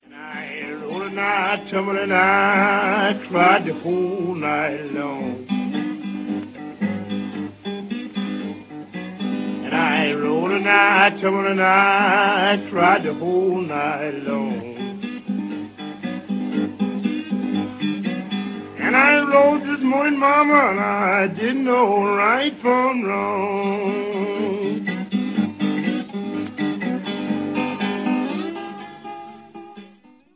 Memphis Country Blues, Vol. 1 (Memphis Archives) - 1994